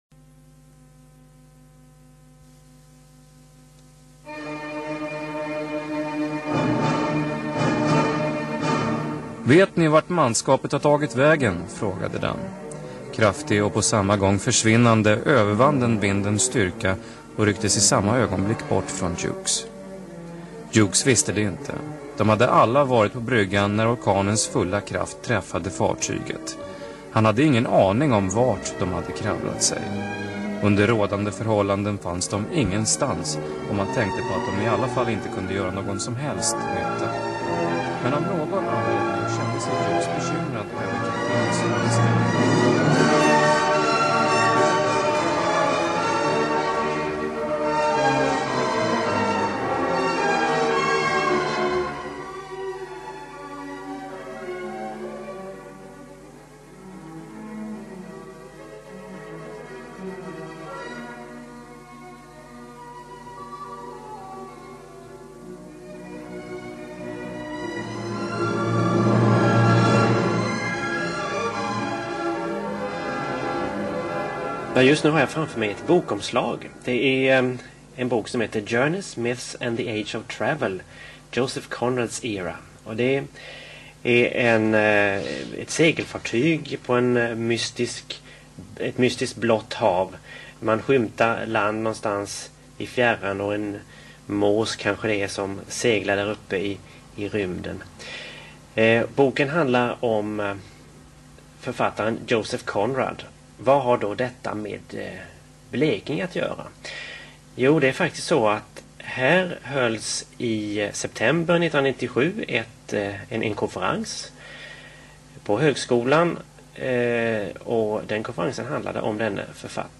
Joseph Conrad. Radioprogram från år 1998 i Radio Blekinge.